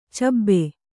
♪ cabbe